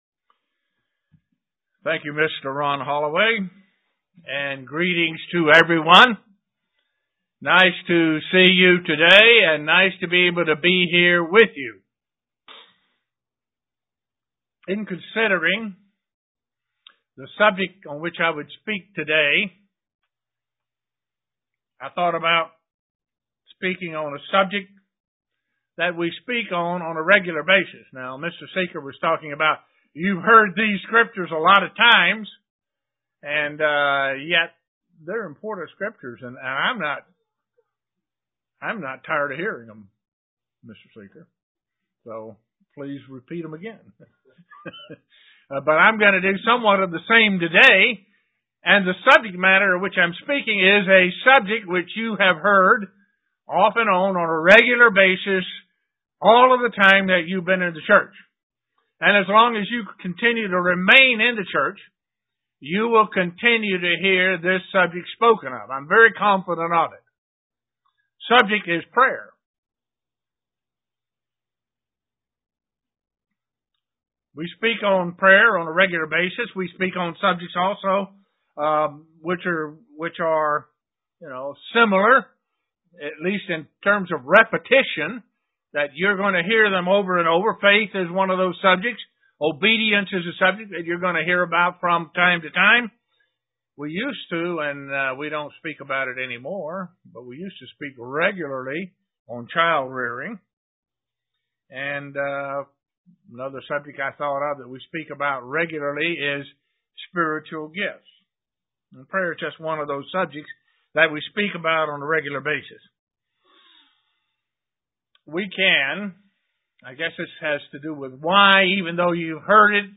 Given in Elmira, NY
Print Four reasons and benefits of prayer UCG Sermon Studying the bible?